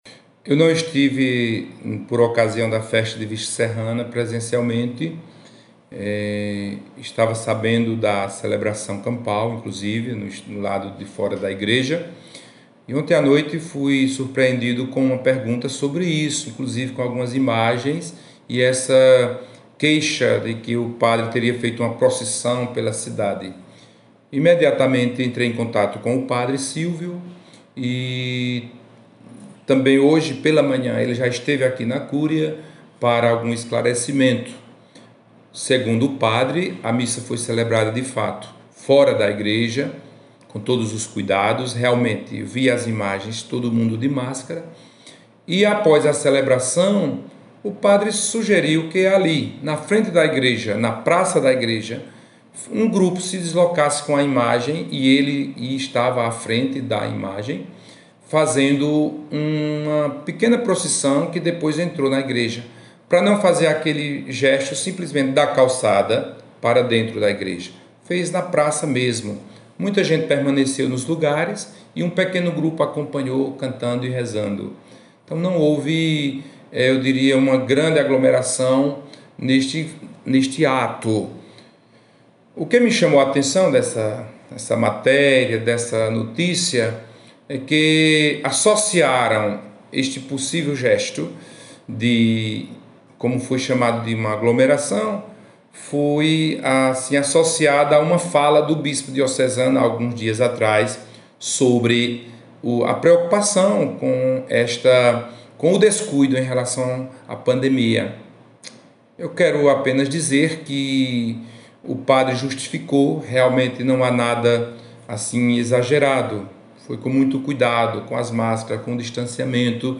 Áudio da Rádio Universidade FM